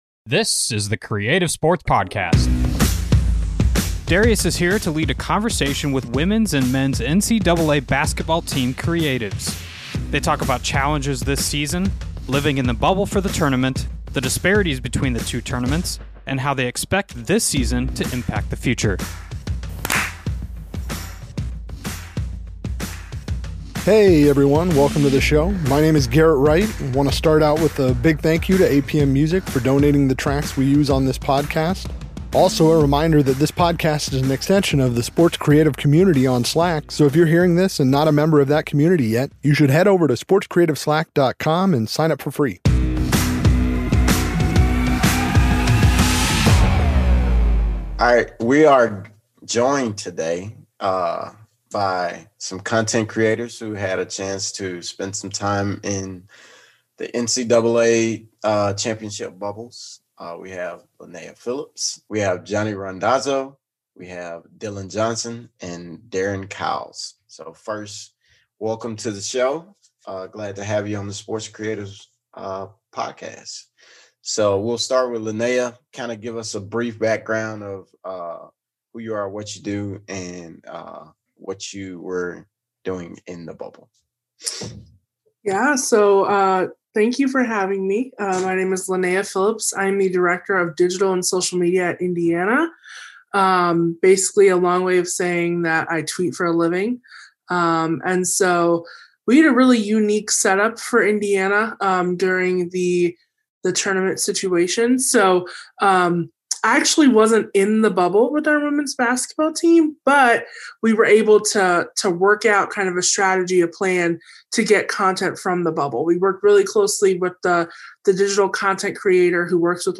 leads a conversation with women’s and men’s NCAA creatives